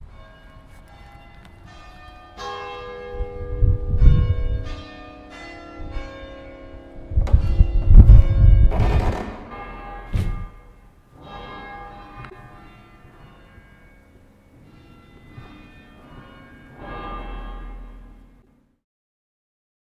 吉他膨胀和噪音 " 吉他膨胀：独奏
描述：东海strat，有延迟，失真，和一点动词，pod xt.
标签： WAV BMP 吉他 颤音 膨胀 东海 16 140 噪声 独奏
声道立体声